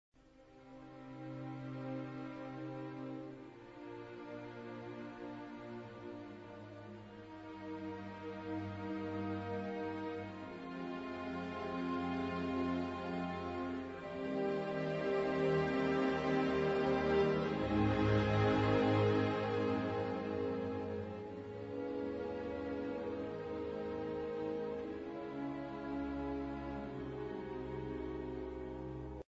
موومان آهسته دوم، آداجیو سوسته نوتو، با هارمونی های ممتدی که توسط سازهای زهی مجهز به سوردین (صدا خفه کن) سراییده می شود آغاز می گردد.